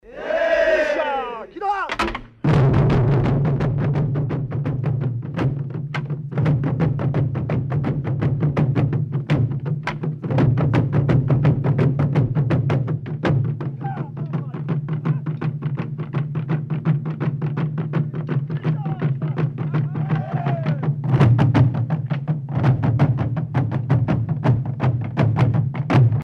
Tambours royaux Ingoma
enregistrement à Bukirasari en 1967